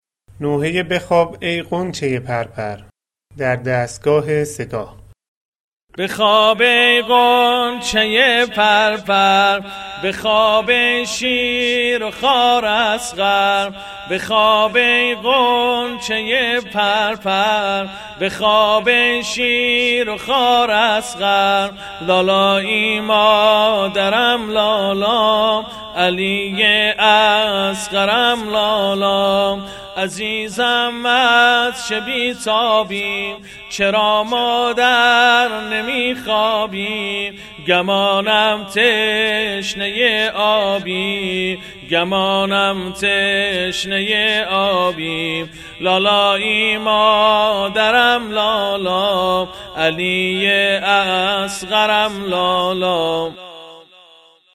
نوحه‌خوانی
دستگاه سه‌گاه: این نوحه در گوشه‌های درآمد و رهاب خوانده شده است: